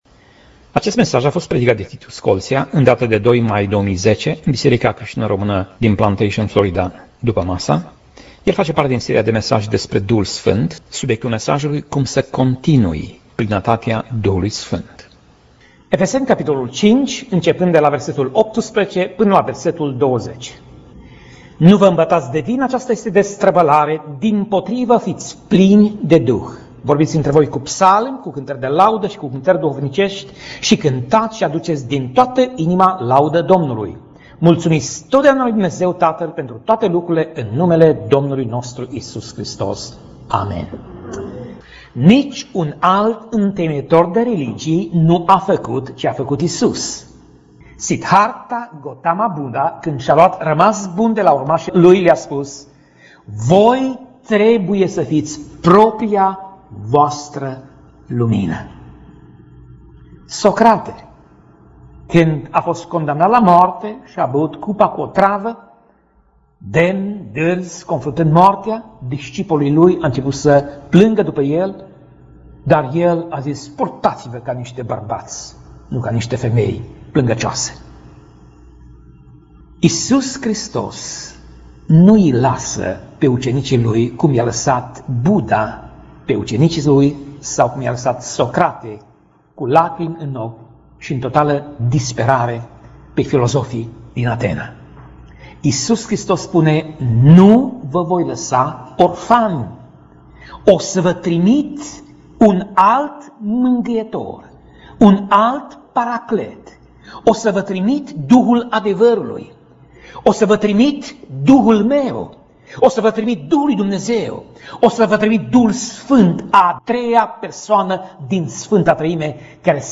Pasaj Biblie: Efeseni 5:18 - Efeseni 5:20 Tip Mesaj: Predica